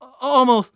dhan_voice_miss.wav